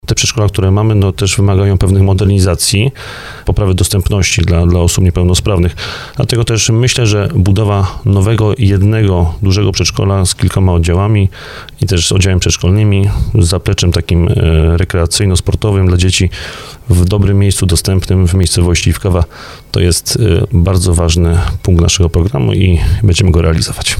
Jak mówił na antenie RDN Małopolska wójt gminy Iwkowa Bartłomiej Durbas, ta inwestycja jest konieczna.